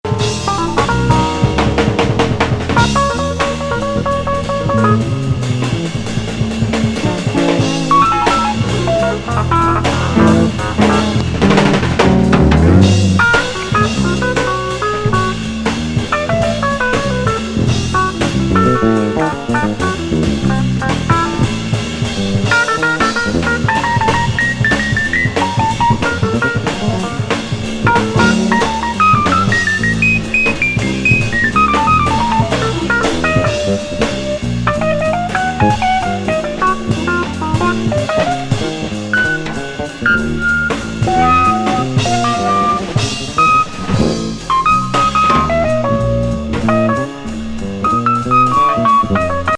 ナイス・エレクトリック・ジャズ!